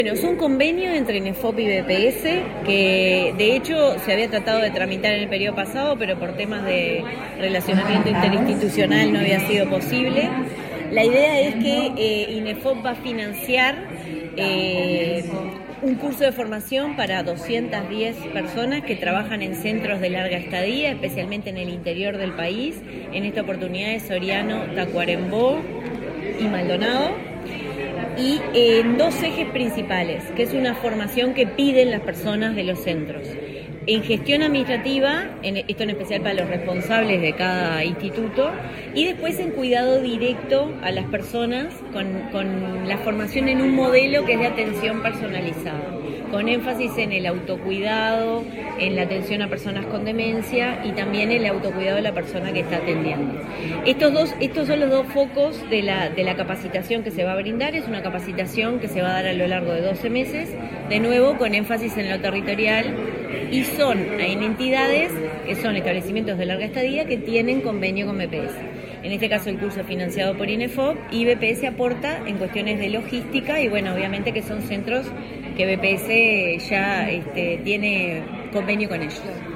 Declaraciones de la presidenta del BPS, Jimena Pardo